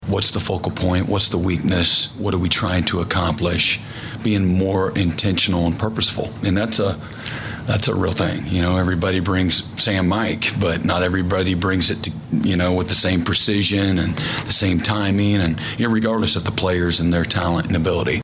OU head football coach Brent Venables met with the media in Norman on Tuesday, as the Sooners need one win in their final three to get bowl eligible.